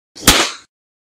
pop.mp3